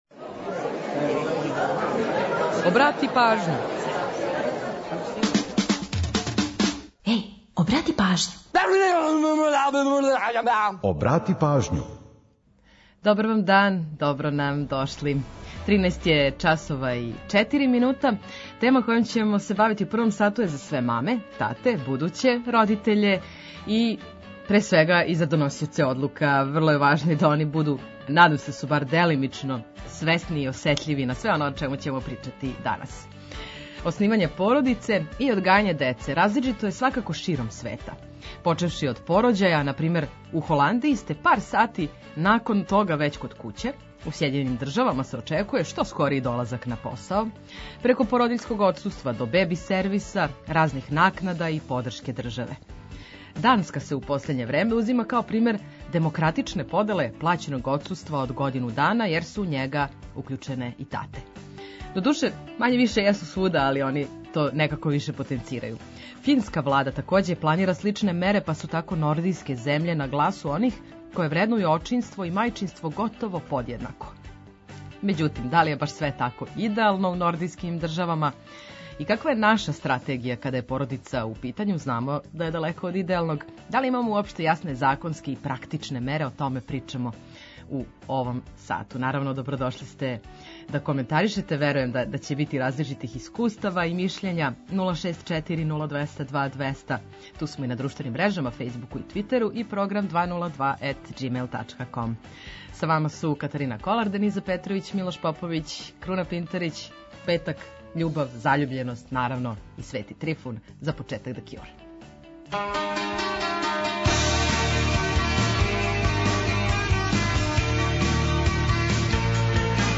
Сервисне информације и наш репортер са подацима о саобраћају помоћи ће многима у организовању дана, а „Културни водич” је ту да предложи које манифестације широм Србије можете да посетите.
Ту је и пола сата резервисаних само за нумере из Србије и региона.